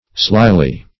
Slyly \Sly"ly\, adv.